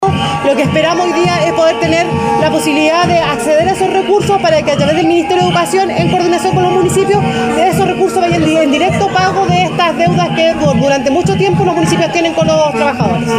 En medio de la manifestación, la seremi de Educación, Romina Maragaño, apuntó sobre las acciones a tomar para poder satisfacer las demandas de los profesores y profesoras. La autoridad de gobierno también fue abucheada por los docentes.